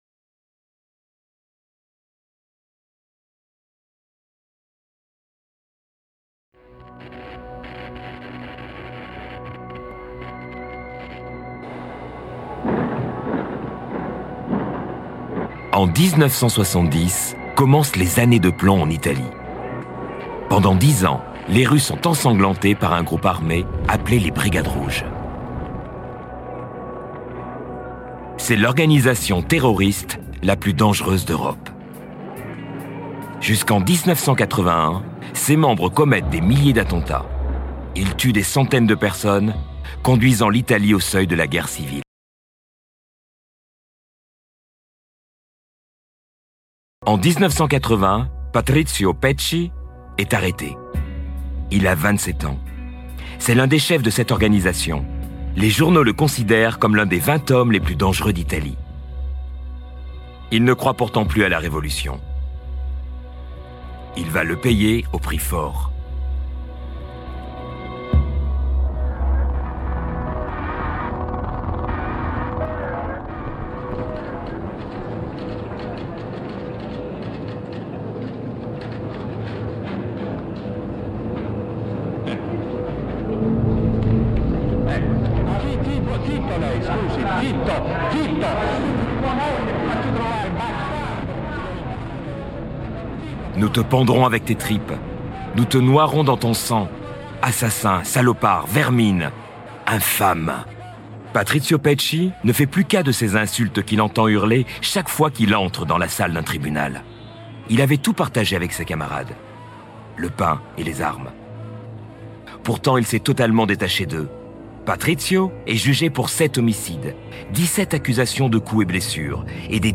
Voix off
Bandes-son